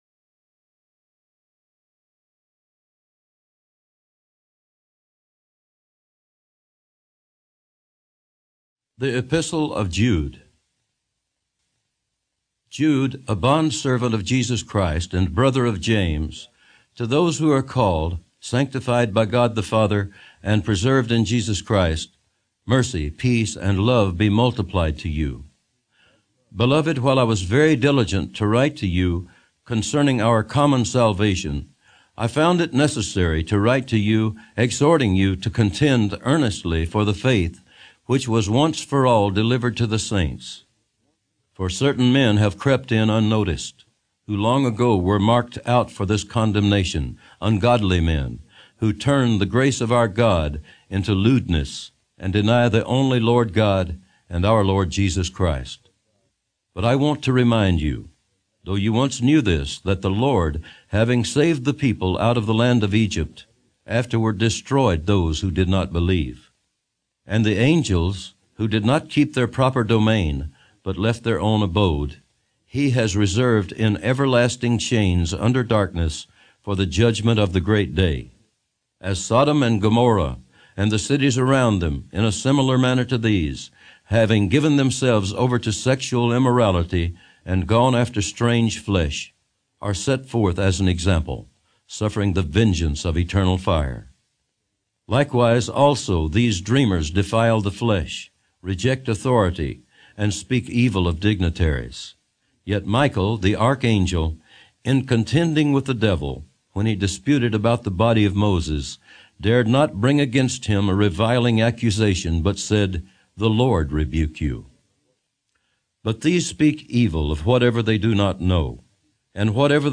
026_JohnnyCash_NKJV_Jude.mp3